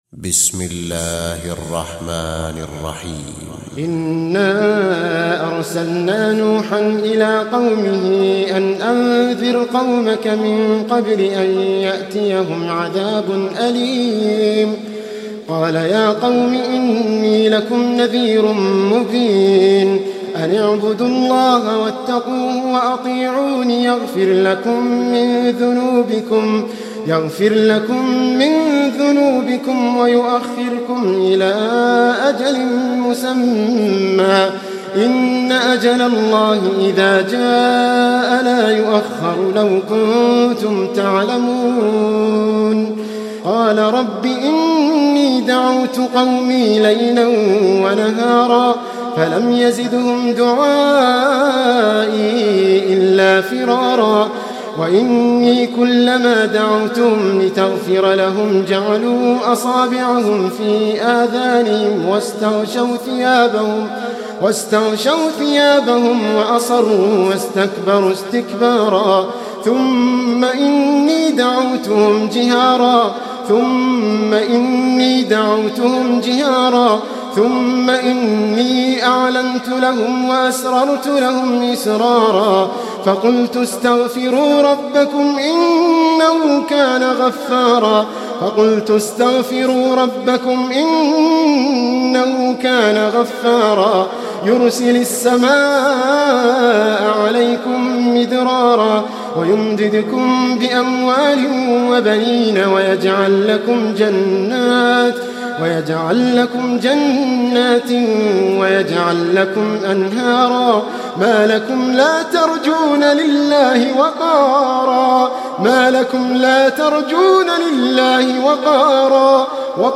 Surah Sequence تتابع السورة Download Surah حمّل السورة Reciting Murattalah Audio for 71. Surah N�h سورة نوح N.B *Surah Includes Al-Basmalah Reciters Sequents تتابع التلاوات Reciters Repeats تكرار التلاوات